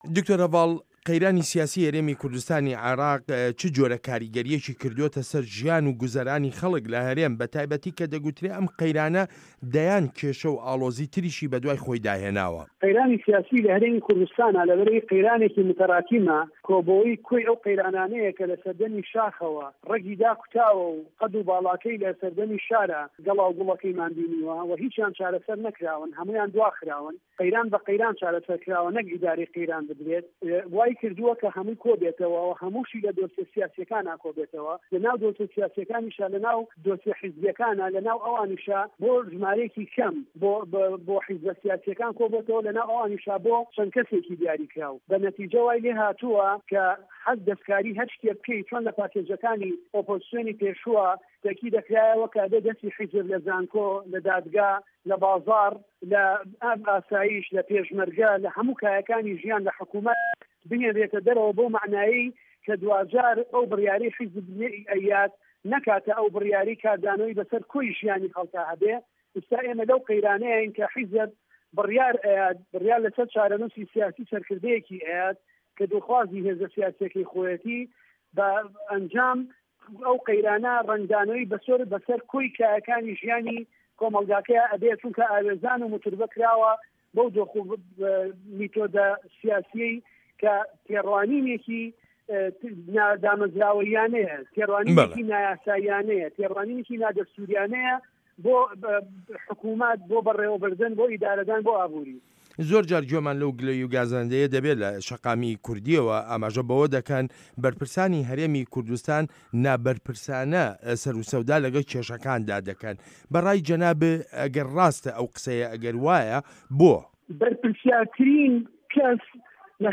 وتووێژ لەگەڵ دکتۆر هەڤاڵ ئەبوبەکرد